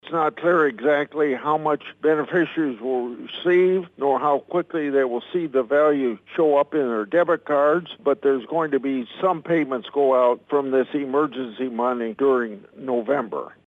Grassley made his comments this (Monday) afternoon during his weekly Capitol Hill Report with Iowa reporters.